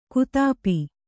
♪ kutāpi